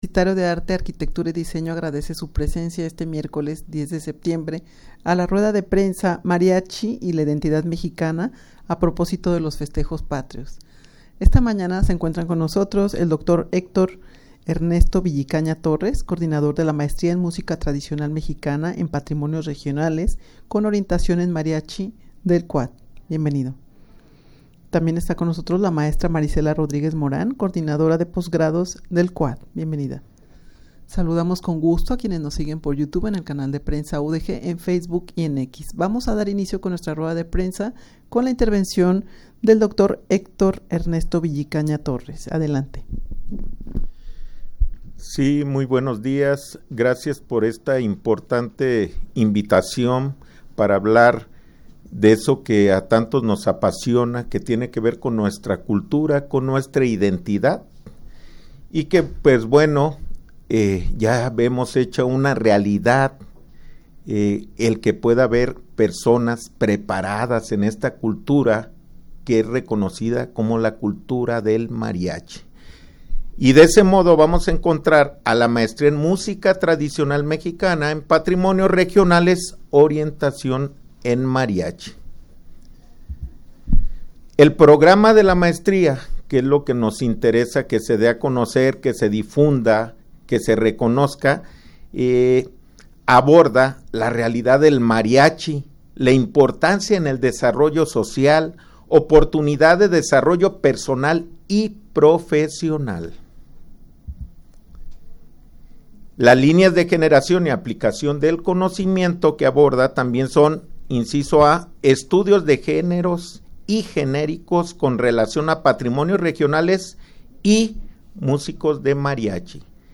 Audio de la Rueda de Prensa
rueda-de-prensa-mariachi-y-la-identidad-mexicana-a-proposito-de-los-festejos-patrios.mp3